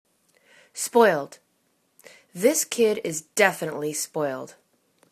spoiled     /spoild/    adj